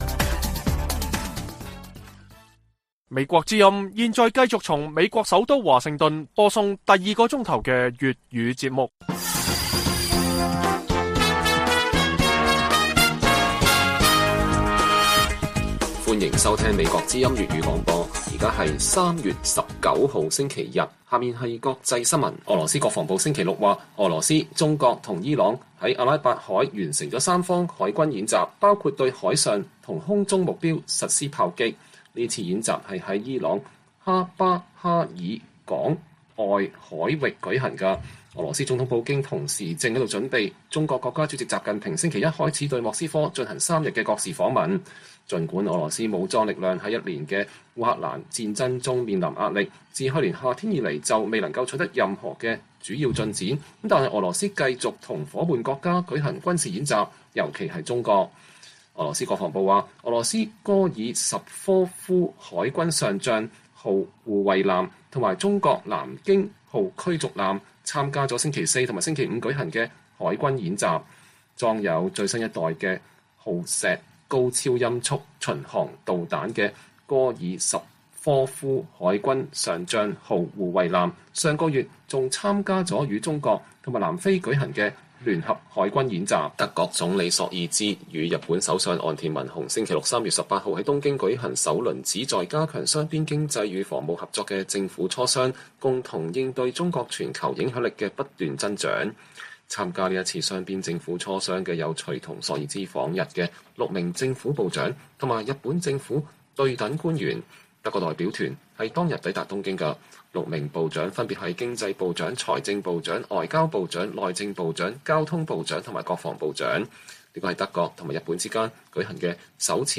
粵語新聞 晚上10-11點 : 矽谷等兩銀行據傳即將標售 美議員呼籲財政部警惕中資介入